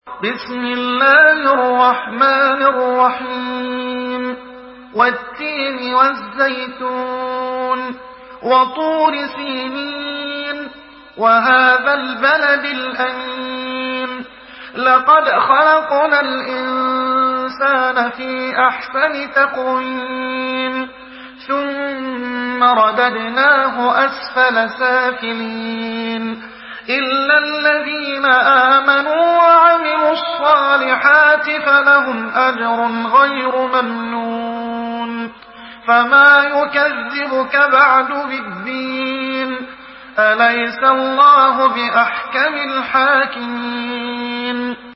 سورة التين MP3 بصوت محمد حسان برواية حفص
مرتل